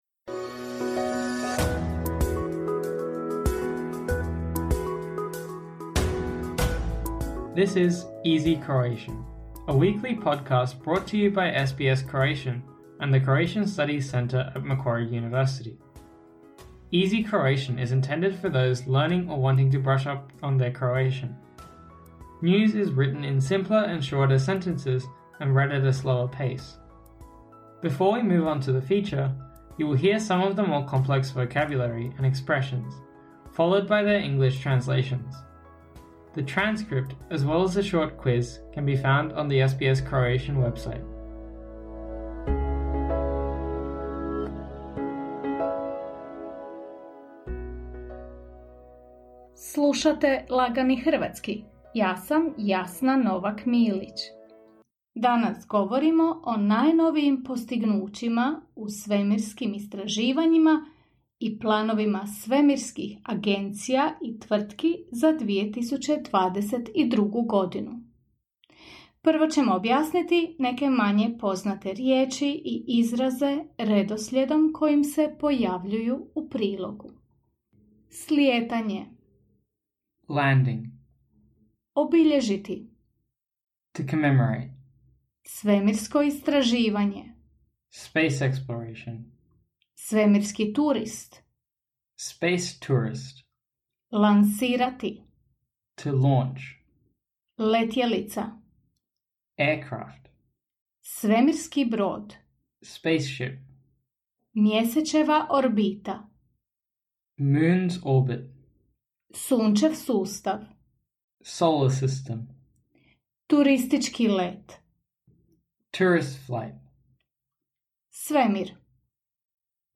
Before we move on to the feature, you will hear some of the more complex vocabulary and expressions, followed by their English translations.
News is written in simpler and shorter sentences and read at a slower pace.…